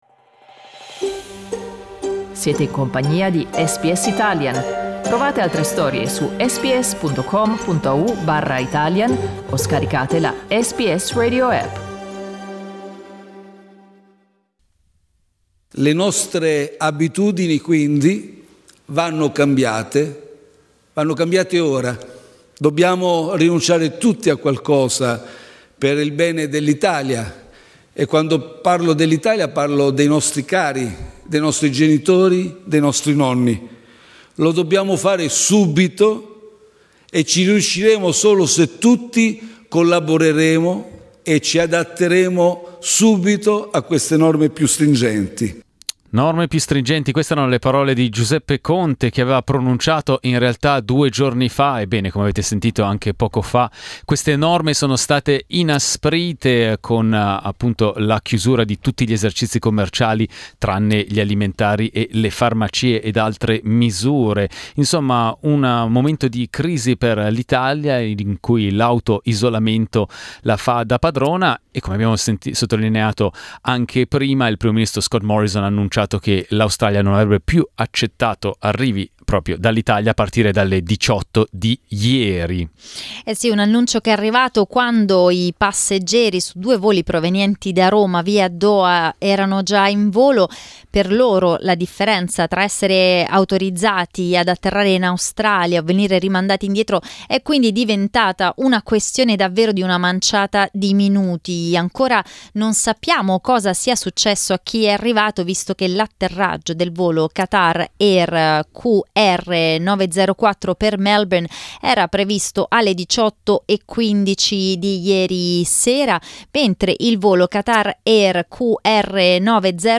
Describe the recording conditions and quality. during the live program